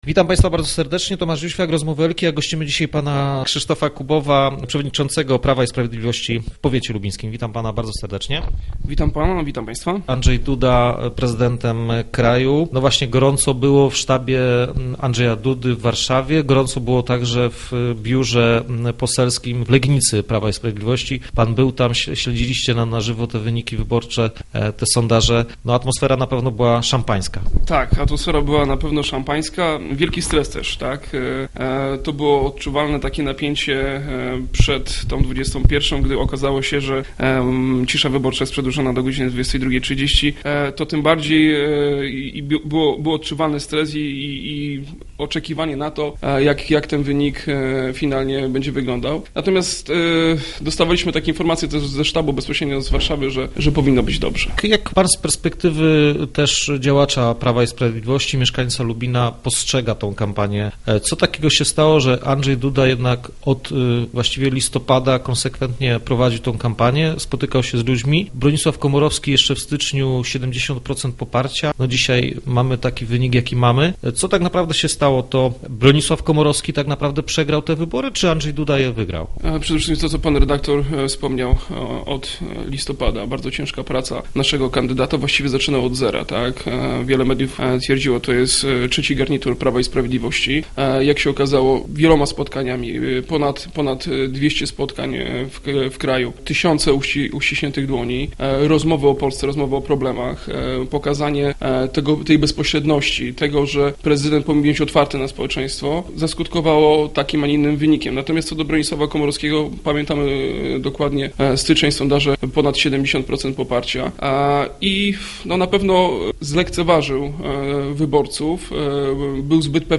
Po tym zwycięstwie PiS zbroi się już do wyborów parlamentarnych. Naszym gościem był Krzysztof Kubów, szef lubińskich struktur PiS.